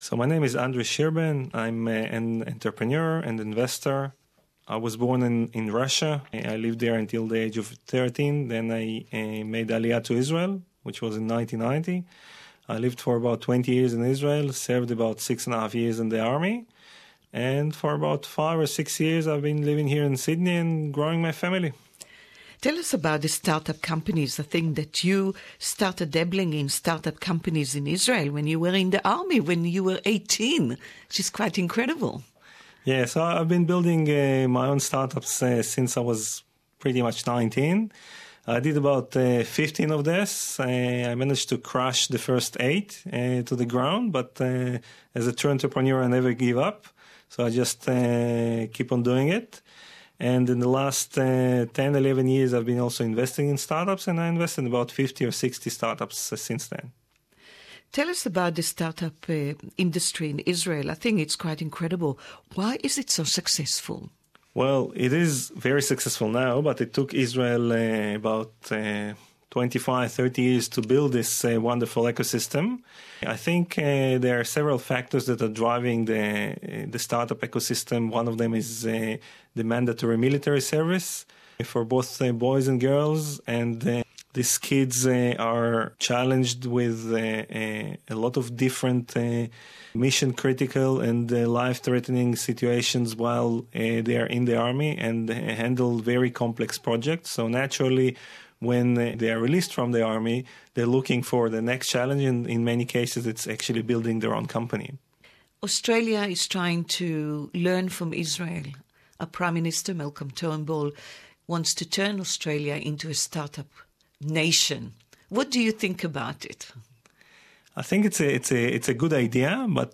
entrepreneur and investor Interview in English